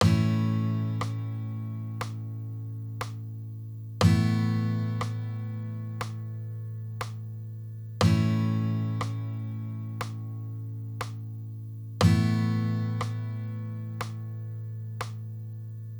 The audio samples that follow each pair will sound out the traditional chord first followed by the easy version.
A and A easy chords
amaj-easy.wav